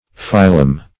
Phylum \Phy"lum\, n.; pl. Phyla.